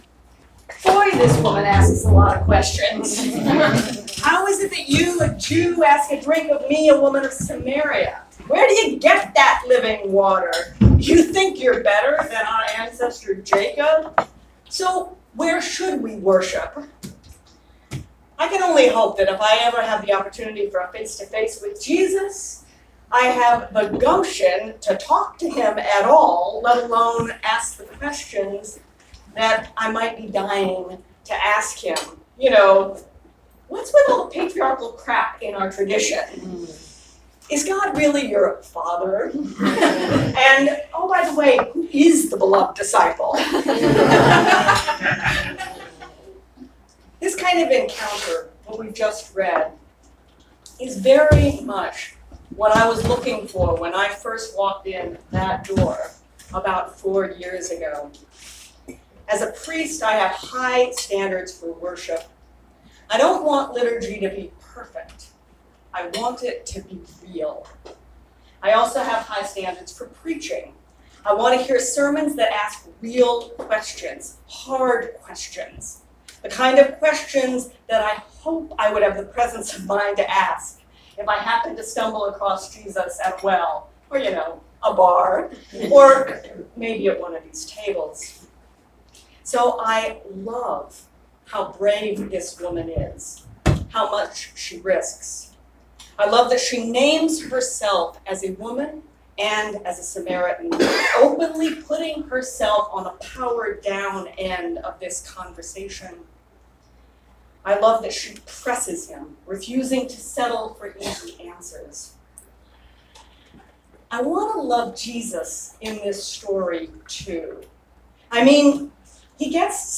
2019 Sermon